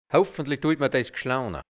Mundart-Wörter | Mundart-Lexikon | hianzisch-deutsch | Redewendungen | Dialekt | Burgenland | Mundart-Suche: G Seite: 15